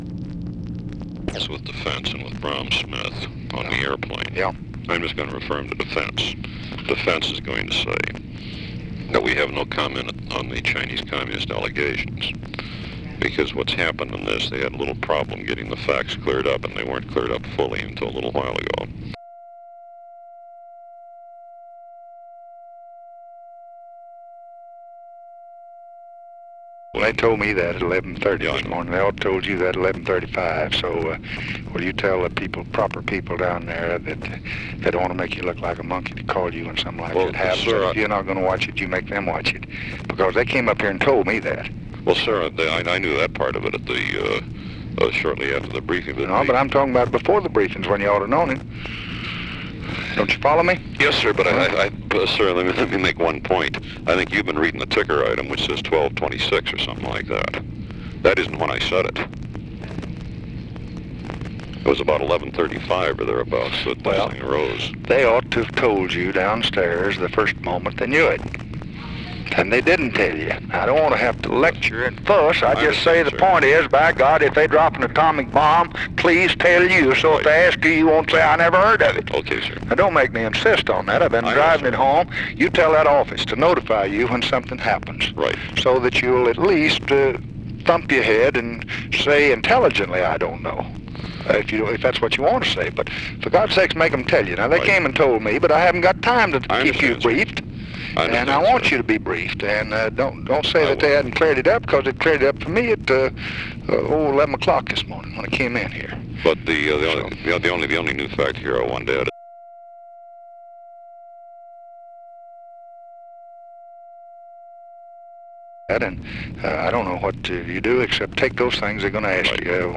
Conversation with GEORGE REEDY, November 16, 1964
Secret White House Tapes